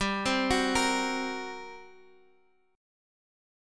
GmM7bb5 chord